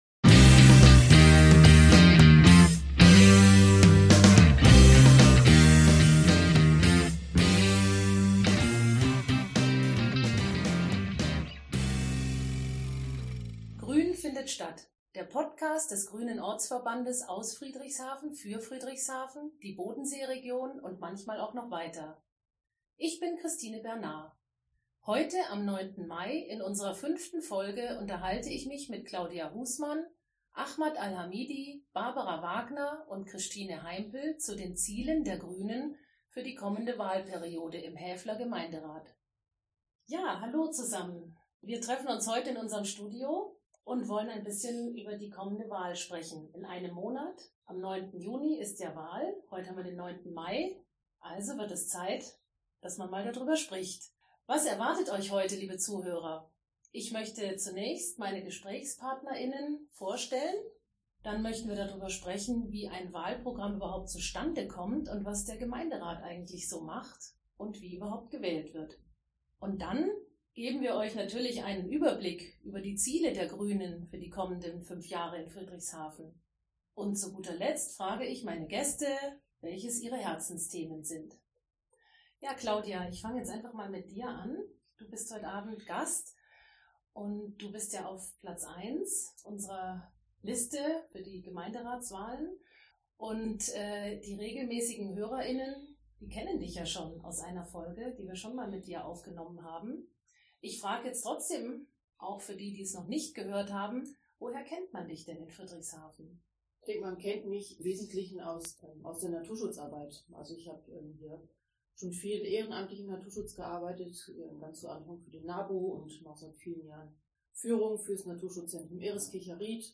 Diesmal hatten wir leichte technische Probleme. Deshalb entspricht die Tonqualität nicht ganz unserem Standard. In der fünften Folge haben wir gleich vier Gäste.